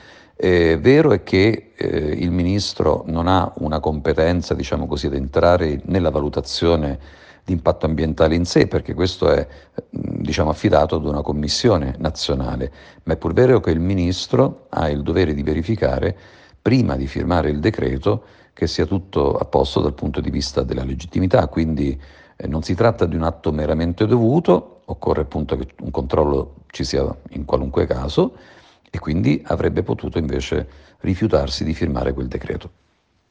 costituzionalista esperto di Diritto dell’Ambiente